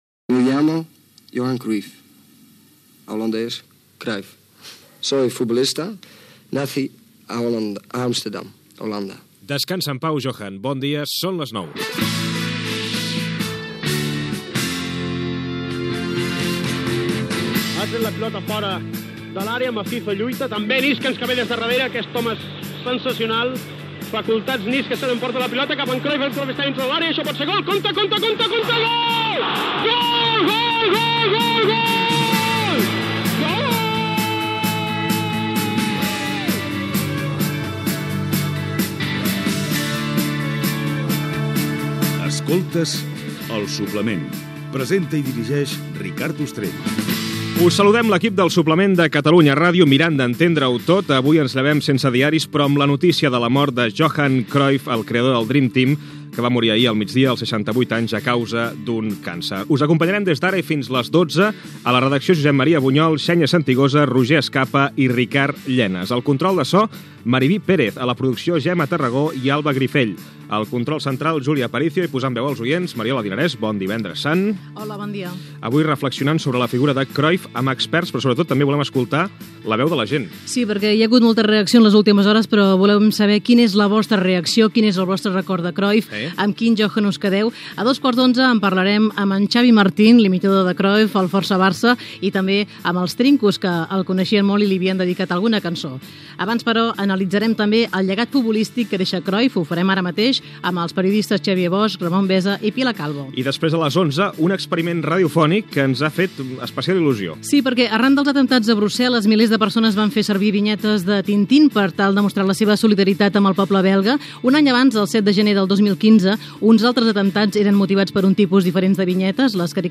Careta del programa, equip, sumari de continguts, indicatiu del programa, la mort de Johan Cruyff, tertúlia amb els periodistes